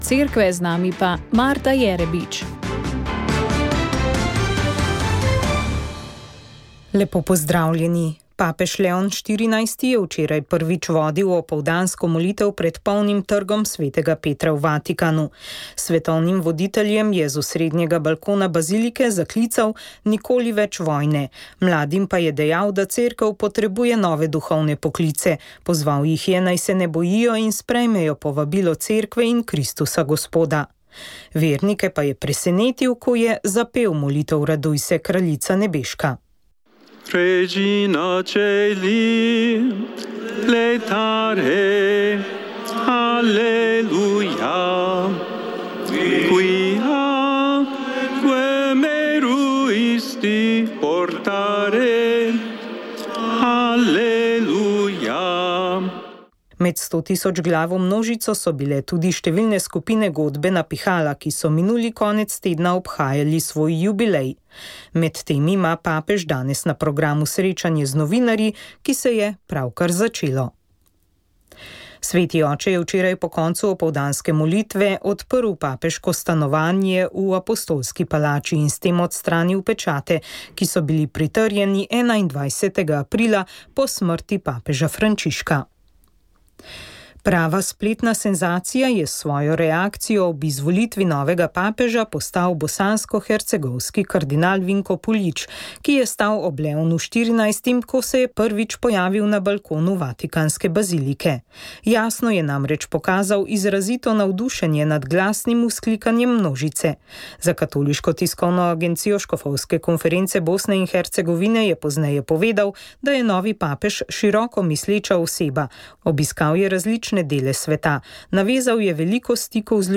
Radio Ognjišče info novice Informativne oddaje VEČ ...